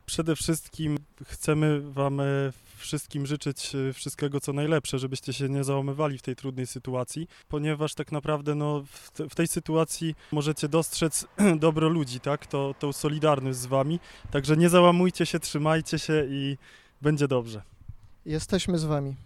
Mieszkańcy też chcieli przekazać słowa otuchy powodzianom.